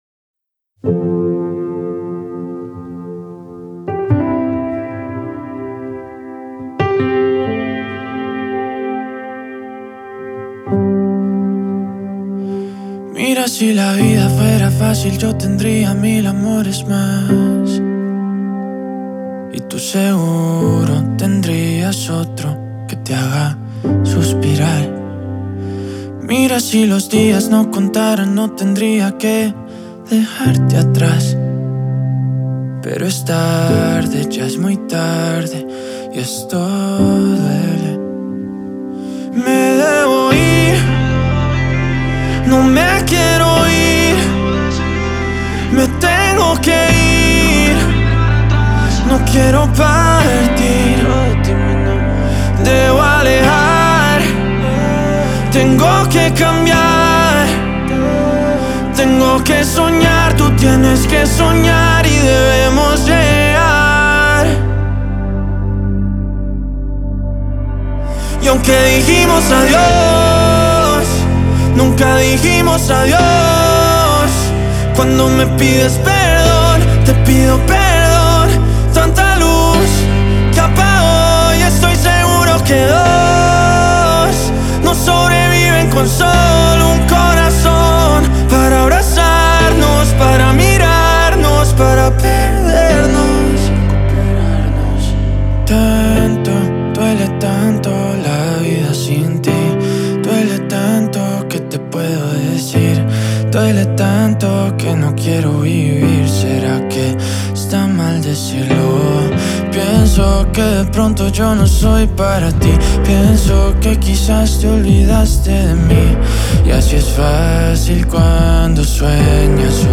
проникновенная баллада
латин-поп